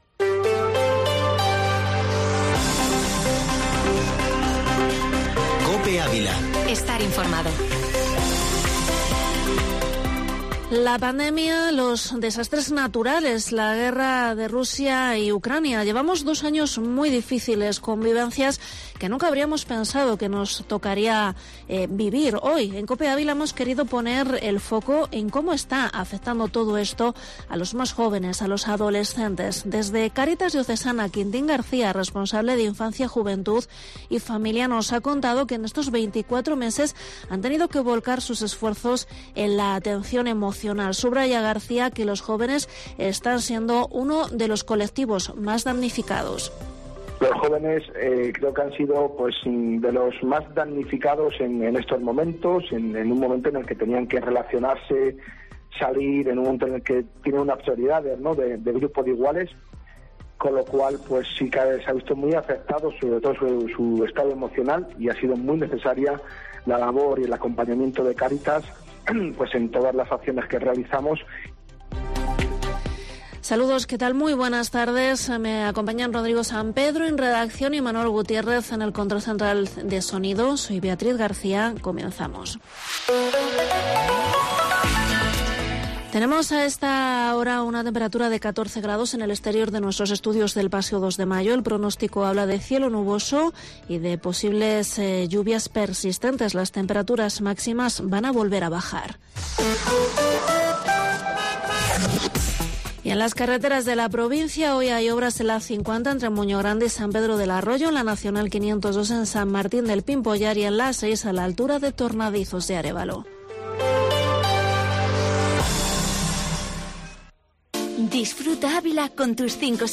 Informativo Mediodía Cope en Avila 29/3/2022